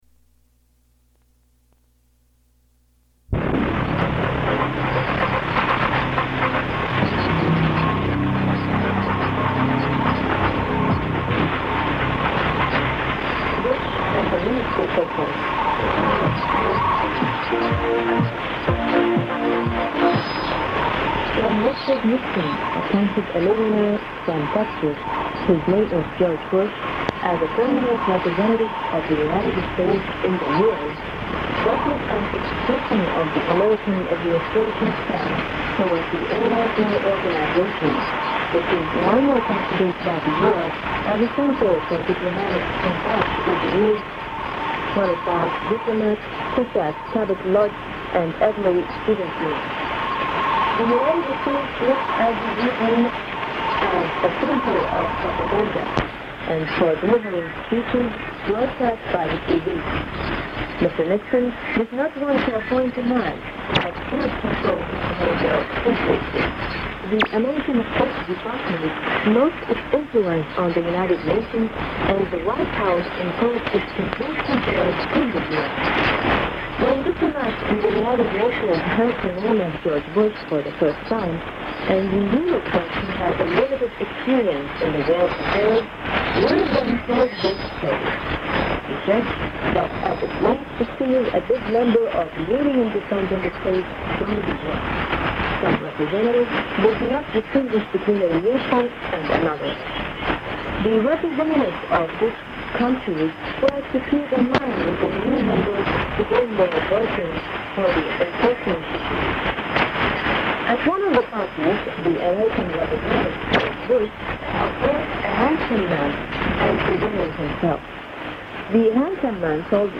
Live, off-air, approximately twenty-minute recording of the Voice of Peace from Baghdad on 29 December 1990 beginning at 21:40 UTC on a shortwave frequency of 11860 kHz.
The recording is an example of the news and music programming. It is not known if the female announcer is the famous Baghdad Betty or someone else. Reception of the broadcast was poor to fair with slight interference and fading. At 21:58 UTC, there is interference splash from WYFR starting up on 11855 kHz.